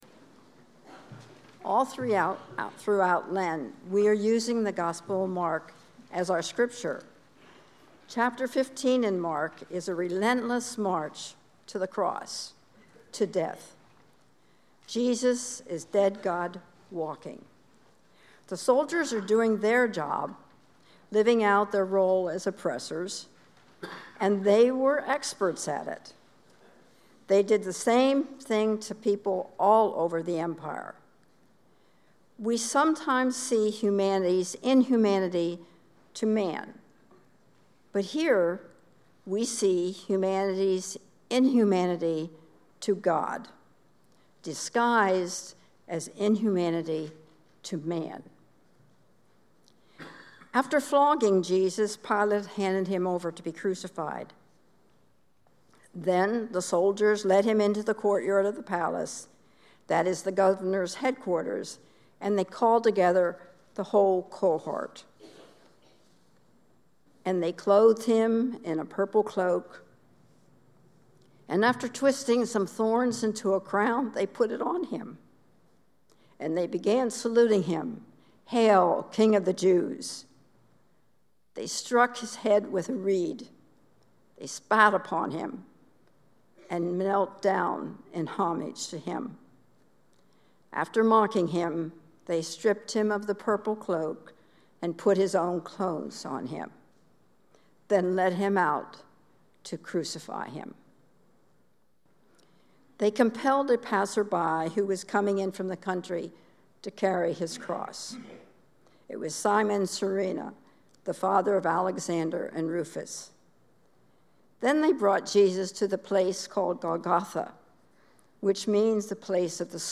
Sermon: Insults and humiliation yet Jesus does not respond. This is God taking the worst of human behavior, our worst and just taking it.